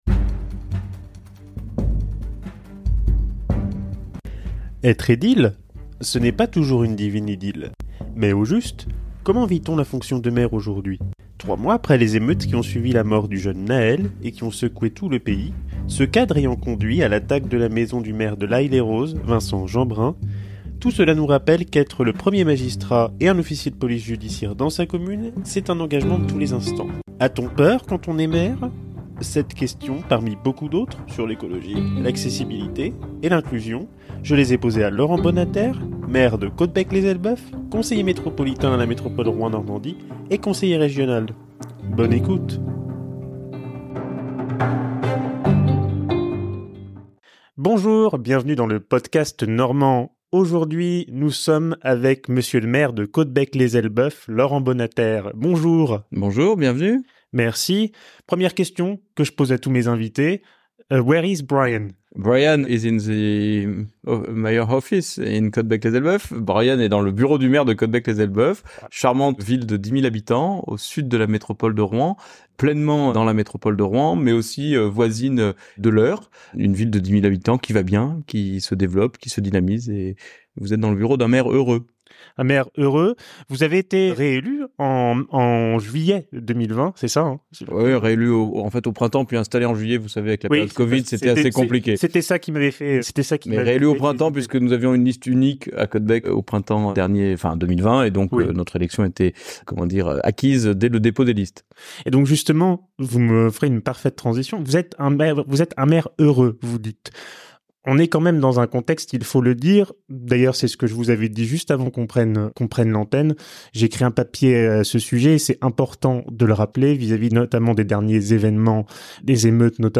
Cette question, parmi beaucoup d’autres, sur l’écologie, l’accessibilité et l’inclusion, je les posées à Laurent Bonnaterre. Maire de Caudebec lès Elbeuf, conseiller métropolitain à la métropole Rouen, Normandie et conseiller régional !Hébergé par Ausha.